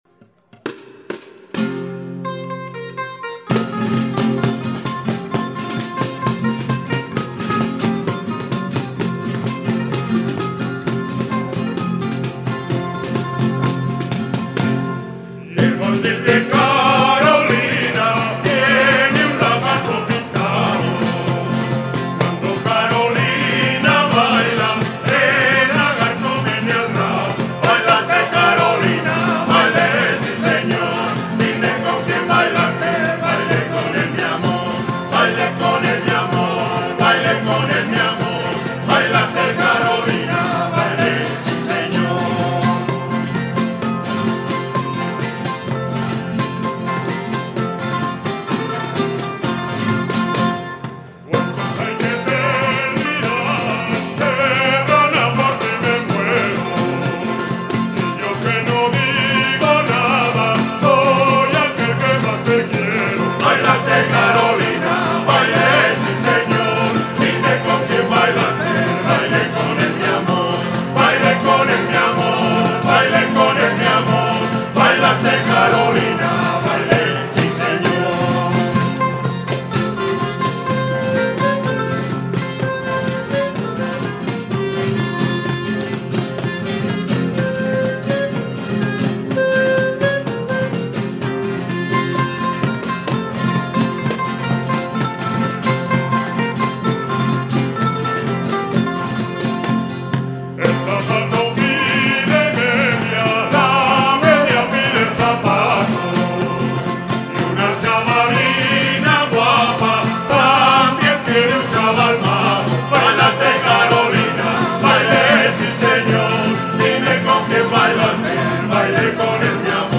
CONCIERTO 2014, en directo